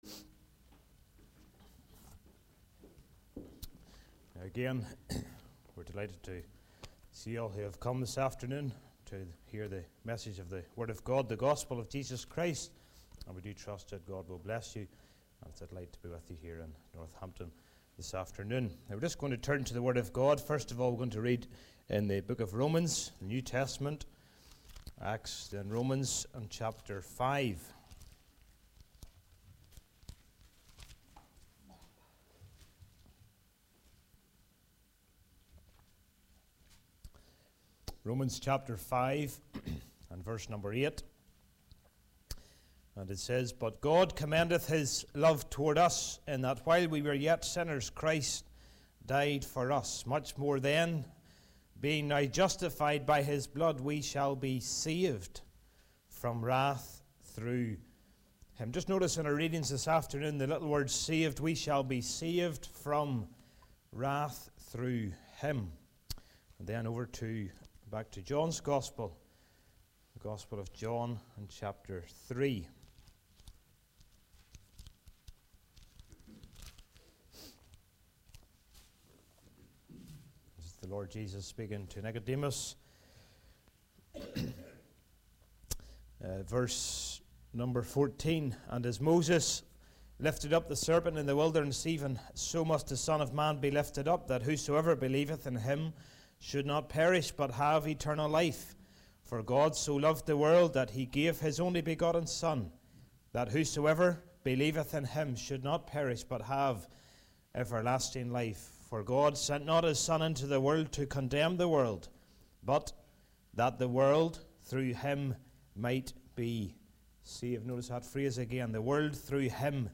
Service Type: Gospel